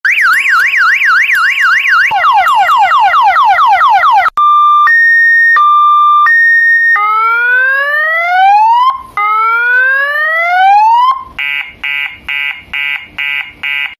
Genre: Nada dering lucu